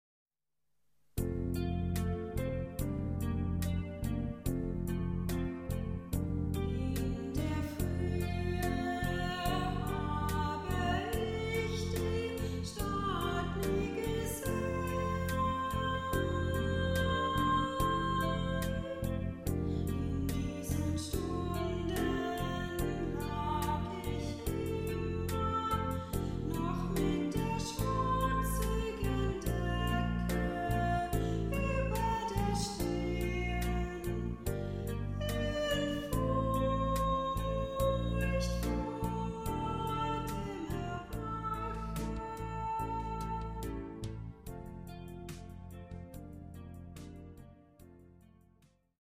klavierlieder
[musical-version]